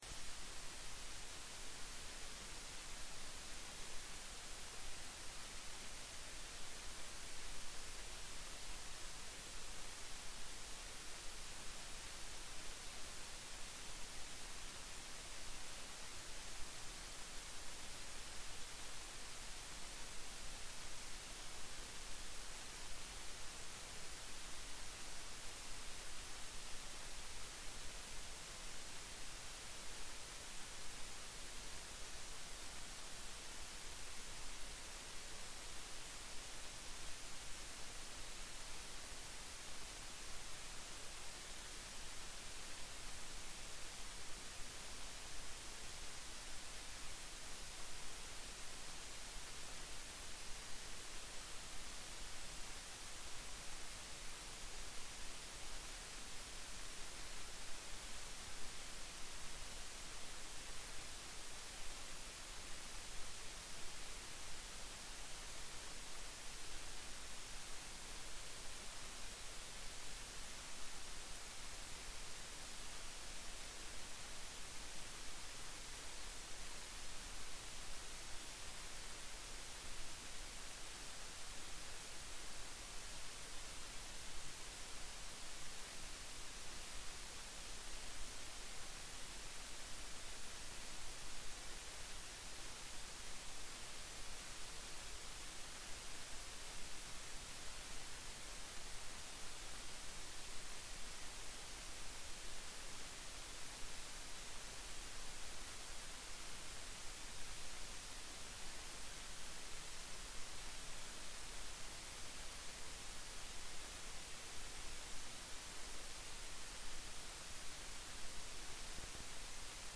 Kjemiforelesning 4
Rom: Store Eureka, 2/3 Eureka